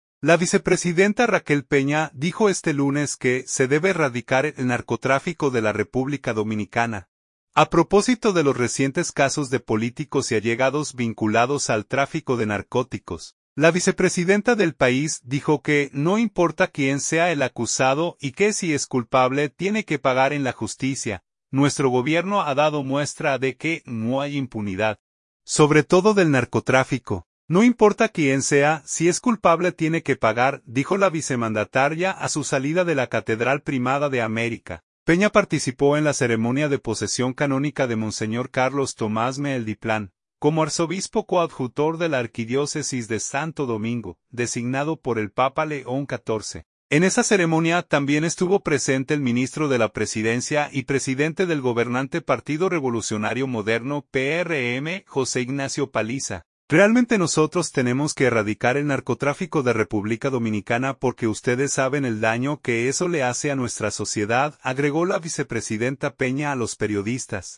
“Nuestro gobierno ha dado muestra de que no hay impunidad, sobre todo del narcotráfico. No importa quién sea, si es culpable tiene que pagar”, dijo la vicemandataria a su salida de la Catedral Primada de América.
“Realmente nosotros tenemos que erradicar el narcotráfico de Republica Dominicana porque ustedes saben el daño que eso le hace a nuestra sociedad”, agregó la vicepresidenta Peña a los periodistas.